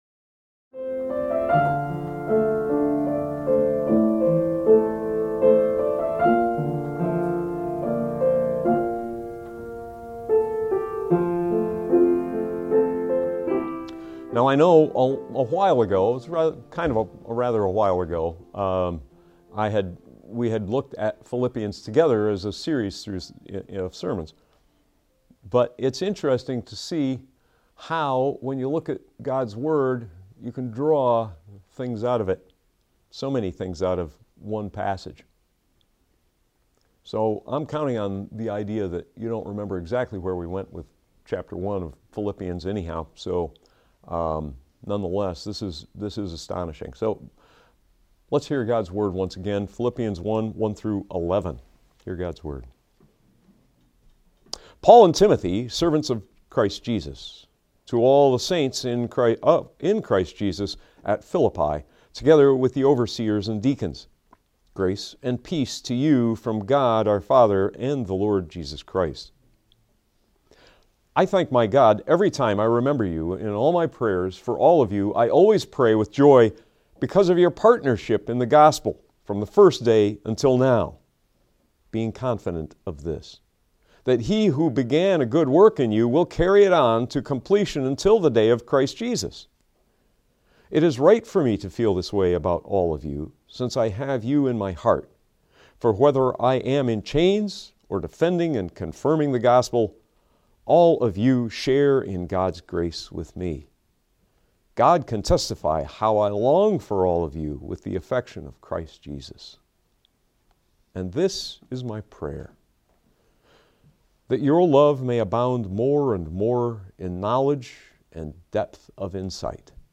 Audio Sermons - Holland Bible Church